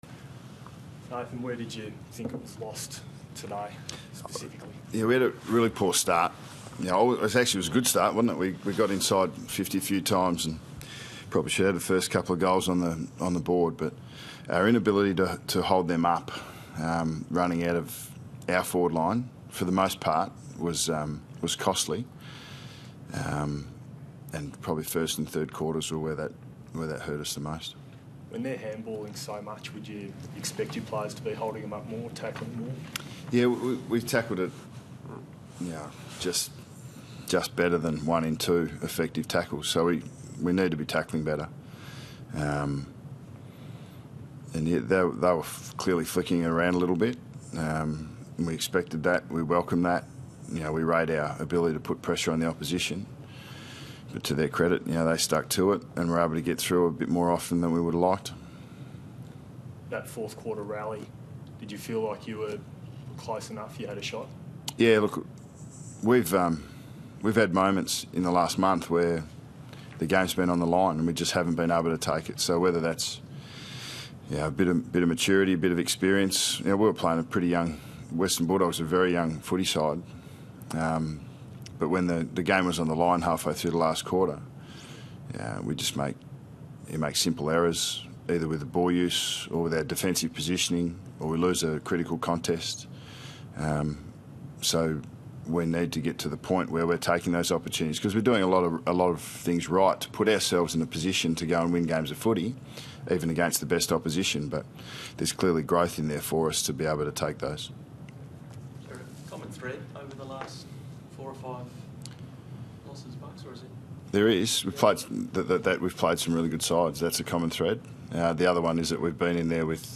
Press Conference: Nathan Buckley Post-Match
Listen to Nathan Buckley's post-match press conference following Collingwood's loss to the Western Bulldogs.